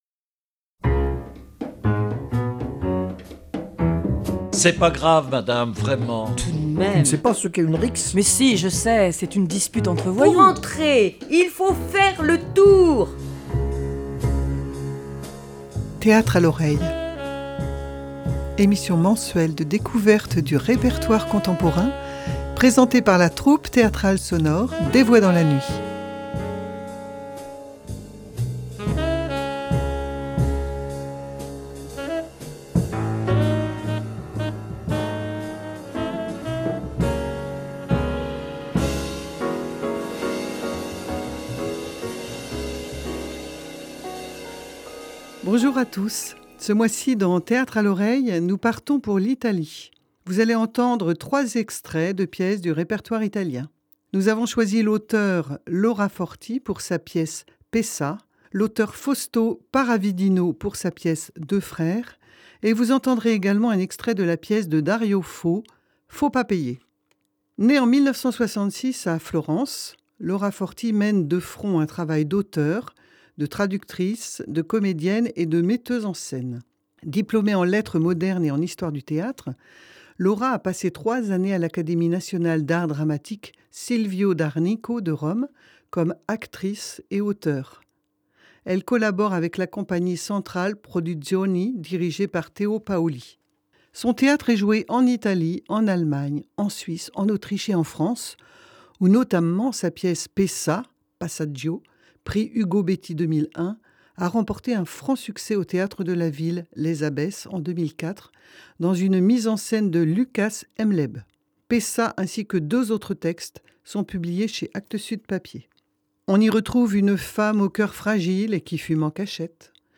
Théâtre à l'oreille présente des extraits de pièces d'auteurs italiens.
Bonjour à tous, ce mois-ci dans théâtre à l’oreille, nous partons pour l’Italie. vous allez entendre trois extraits de pièces du répertoire italien.